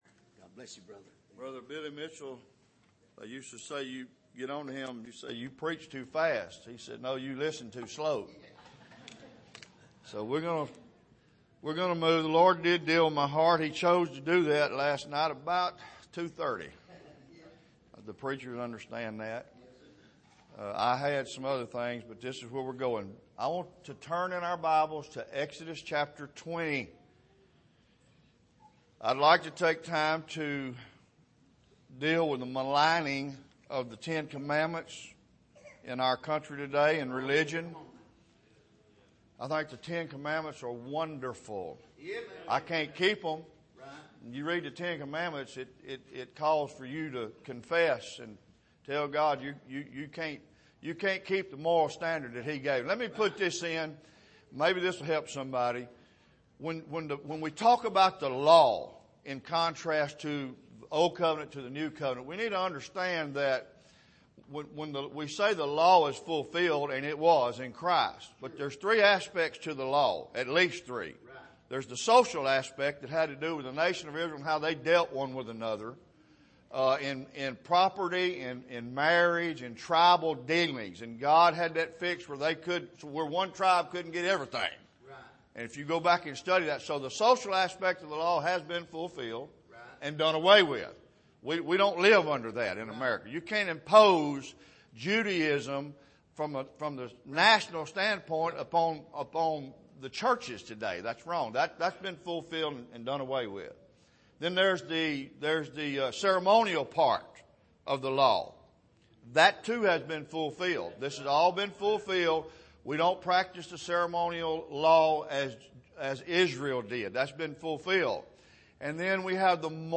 Here is an archive of messages preached at the Island Ford Baptist Church.